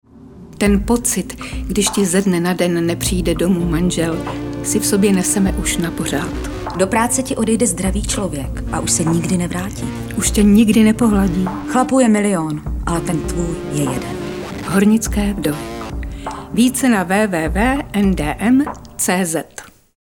Oficiální audiospot NDM